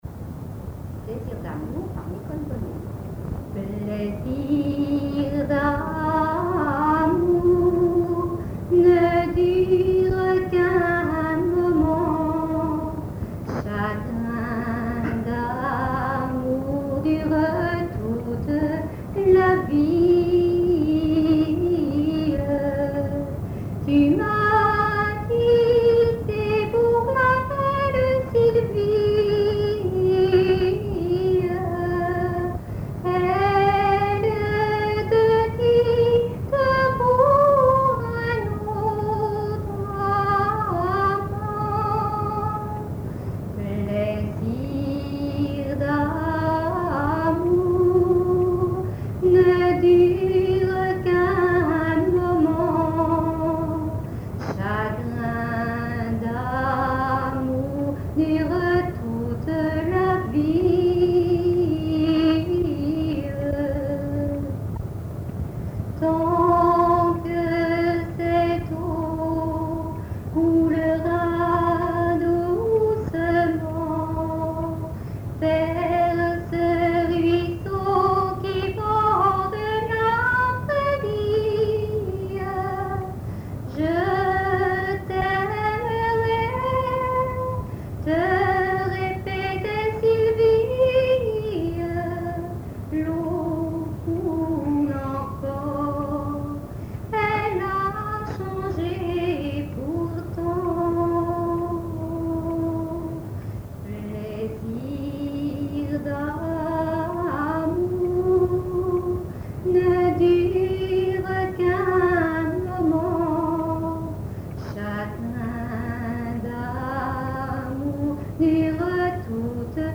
Genre strophique
chansons populaires
Pièce musicale inédite